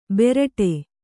♪ beraṭe